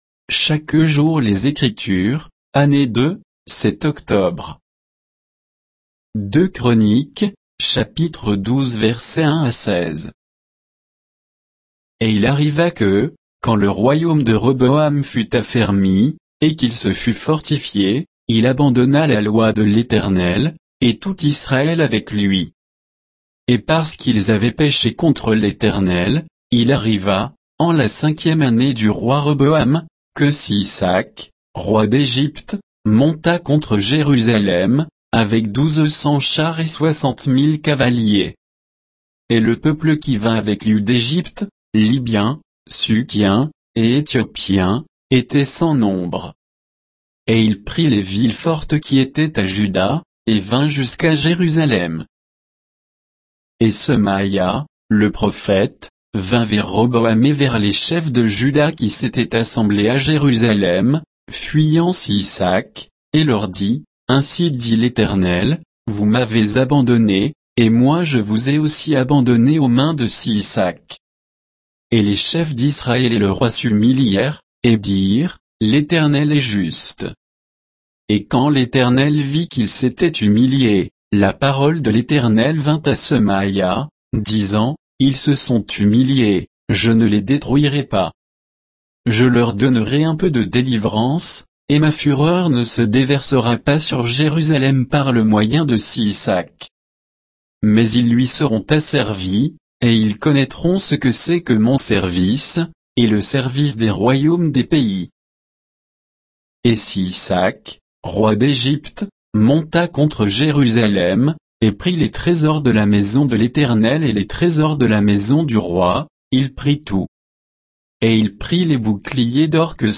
Méditation quoditienne de Chaque jour les Écritures sur 2 Chroniques 12